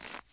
added base steps sounds
snow_3.ogg